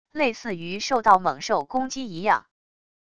类似于受到猛兽攻击一样wav音频